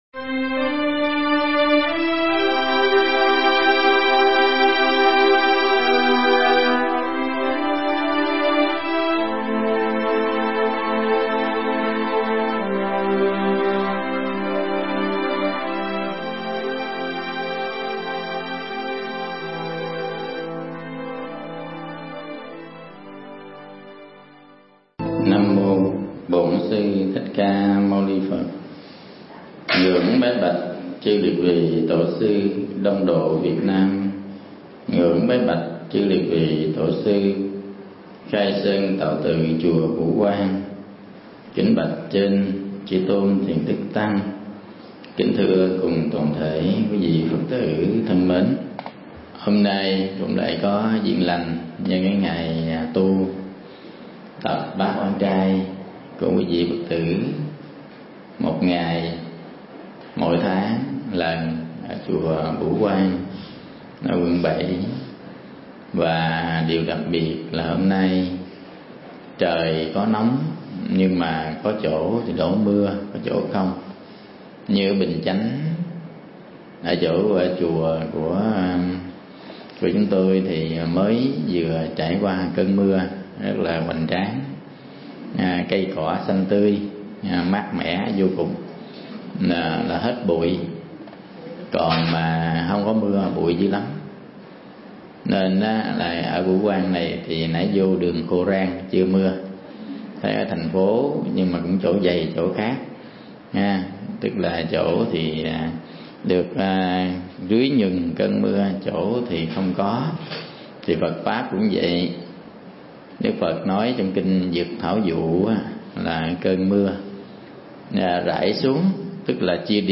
Mp3 Pháp Thoại Cơn Mưa Pháp
giảng tại Chùa Bửu Quang, Trần Xuân Soạn, Quận 7